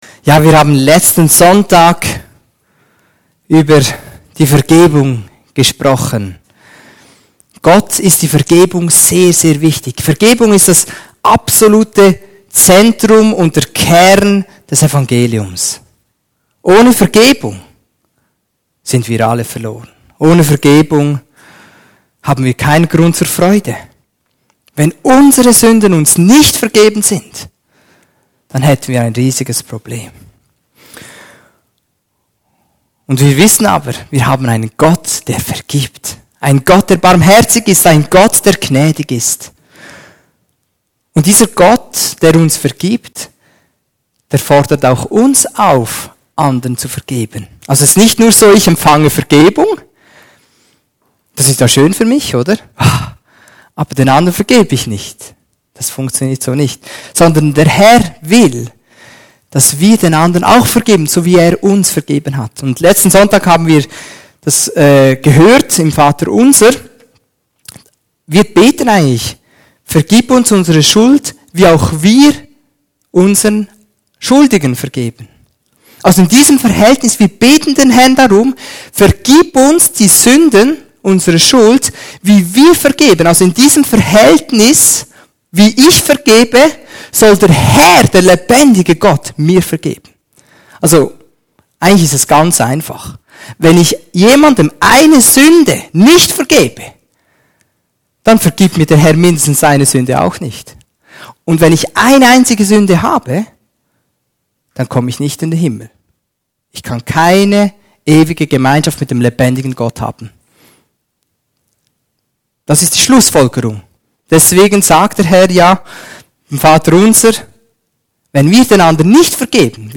Das Thema für die Predigen ist: Versohnung, was Gott will von uns